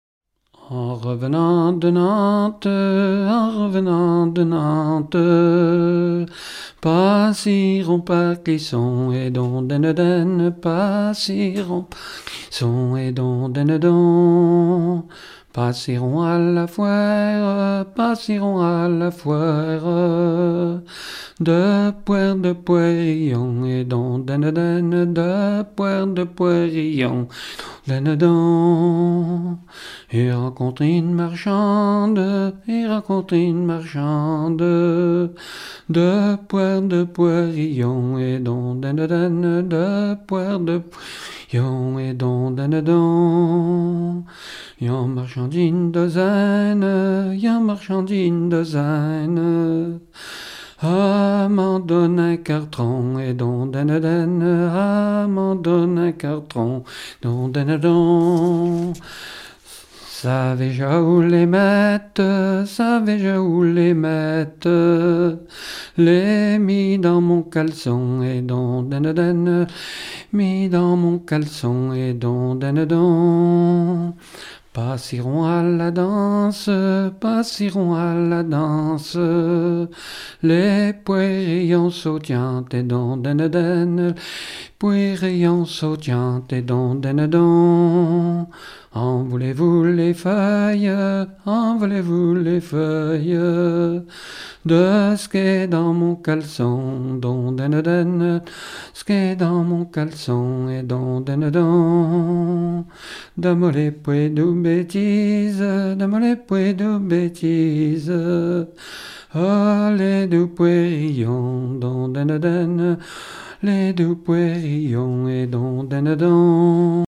Mémoires et Patrimoines vivants - RaddO est une base de données d'archives iconographiques et sonores.
Catégorie Pièce musicale inédite